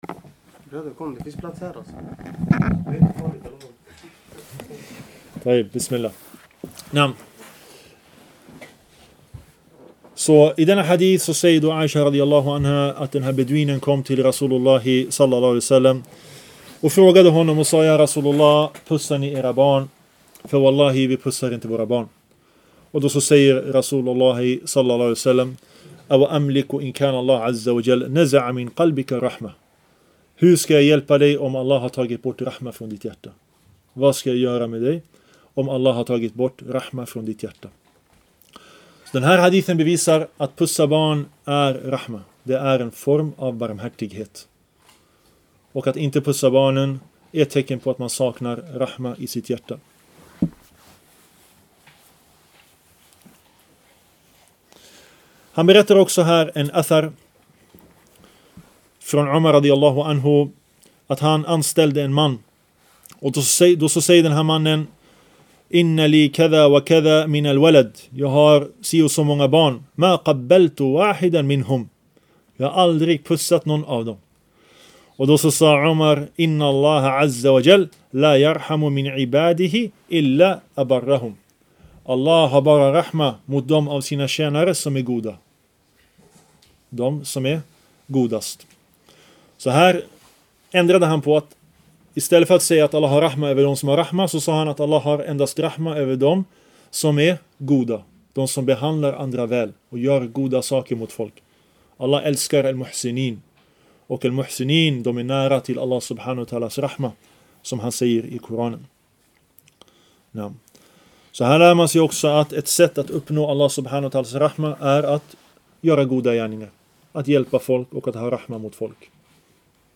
En föreläsning